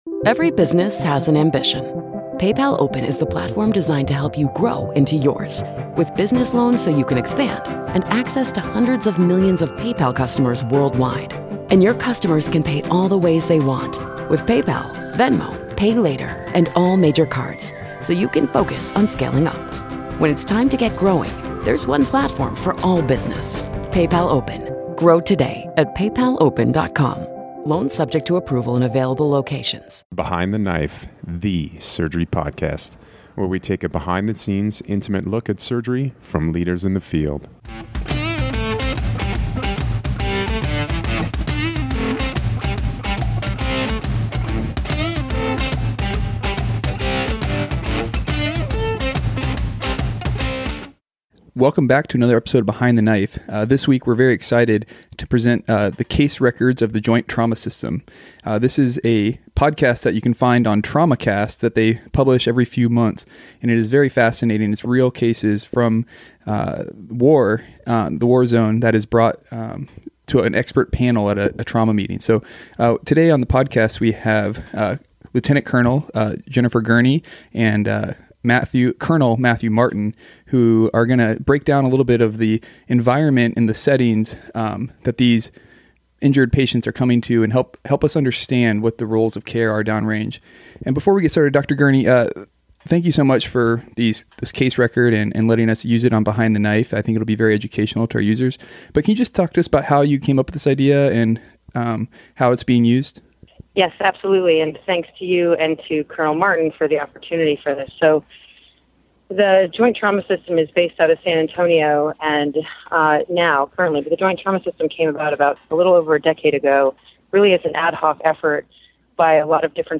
An expert panel of trauma surgeons both civilian and military retrospectively evaluate trauma cases and discuss the complexities of making war time surgical decisions.